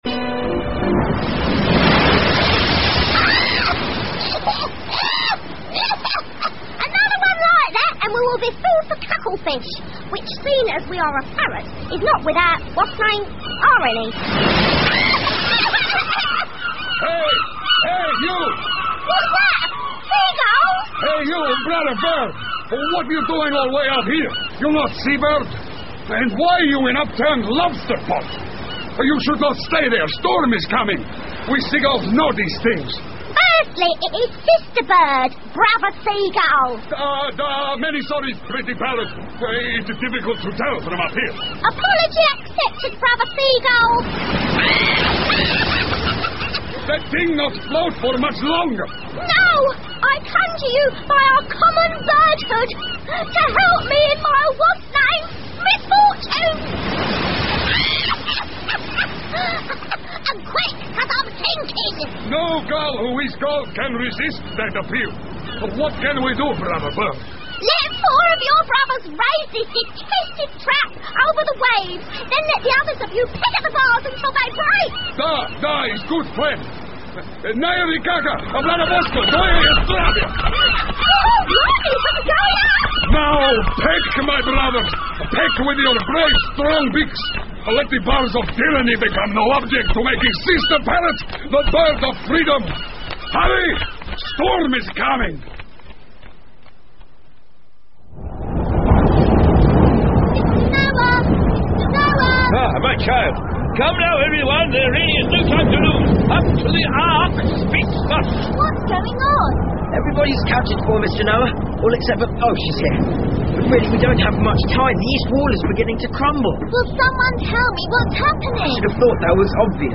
魔法之城 The Magic City by E Nesbit 儿童广播剧 20 听力文件下载—在线英语听力室